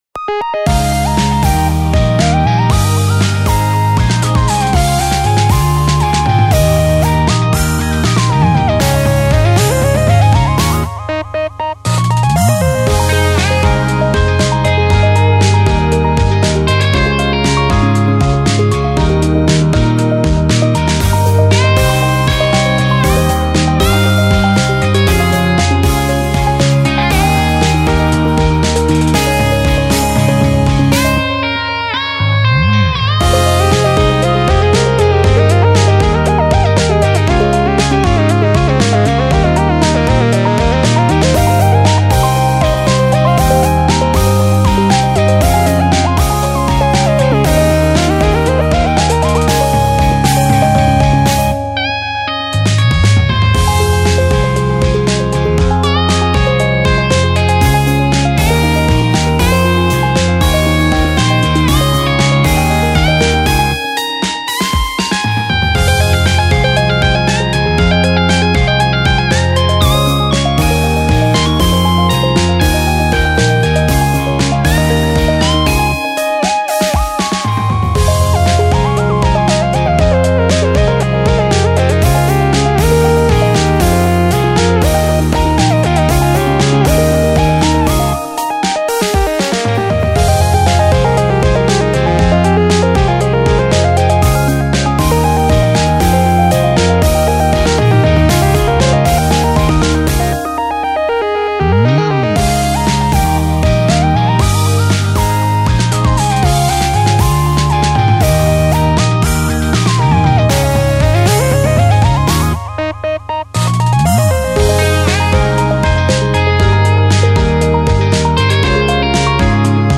ジャンルイージーリスニング
BPM１１８
使用楽器ギター、シンセリード
そのままアレンジするのではなく、エレキギターの新規メロディーを入れております。
エレクトロニック(Electronic)